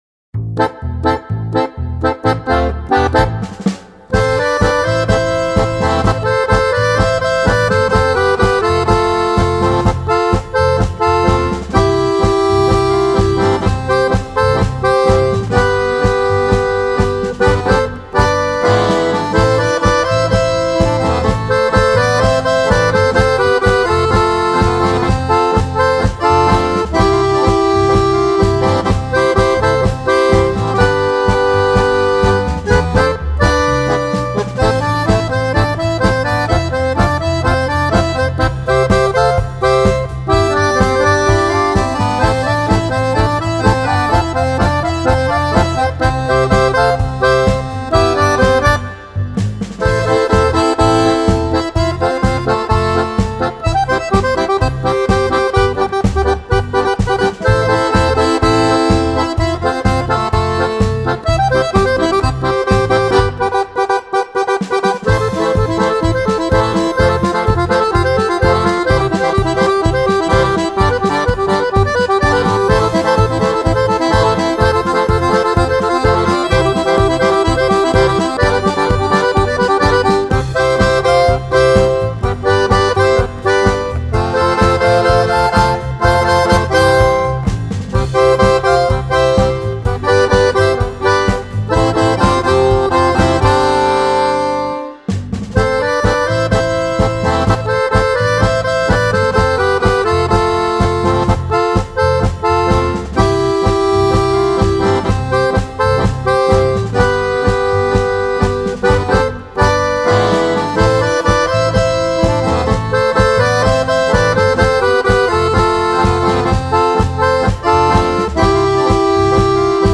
• Moderne Polka im Pop-Style
Akkordeon 1 bis 4
Bass
Drums